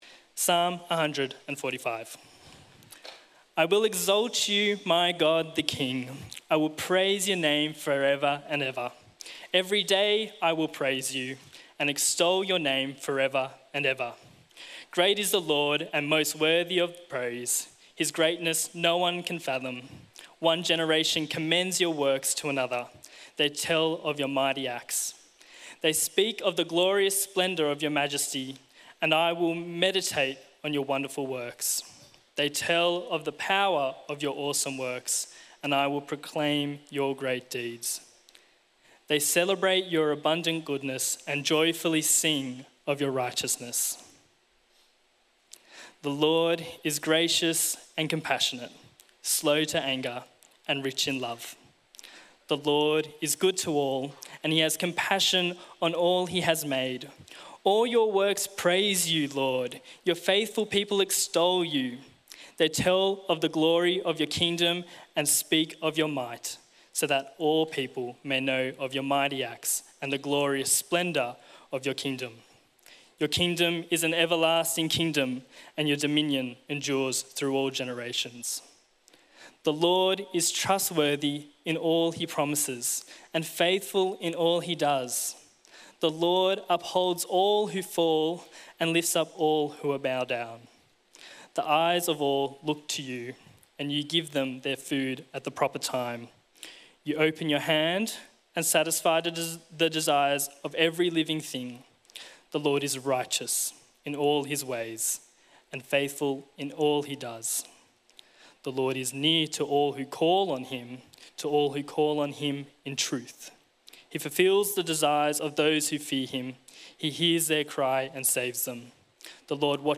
Summer School 2025 I Audio Talk I Greg Anderson - CMS
Greg Anderson, Anglican Bishop of the Northern Territory, speaks on the legacy of CMS Aboriginal mission over a century.